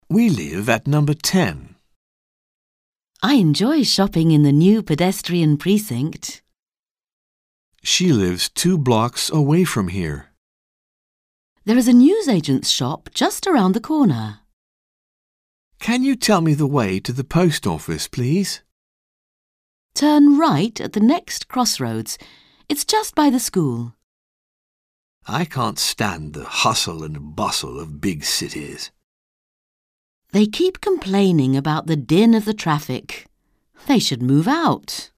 Un peu de conversation - La ville